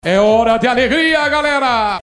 Faustão (Fausto Silva) falando "é hora de alegria, galera" no Domingão do Faustão.